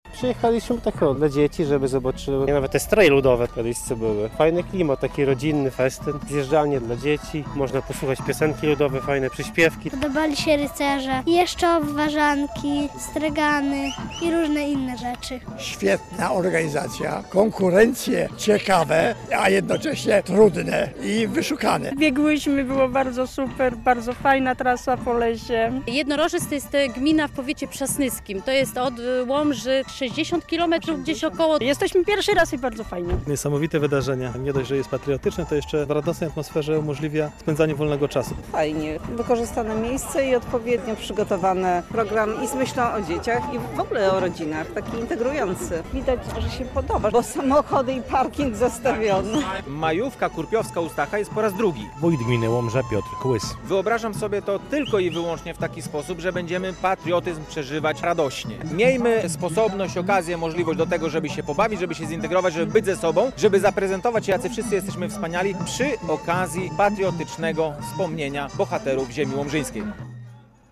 Tłumy ludzi wypełniły Las Jednaczewski koło Łomży podczas "Kurpiowskiej Majówki u Stacha".
Kurpiowska Majówka u Stacha - relacja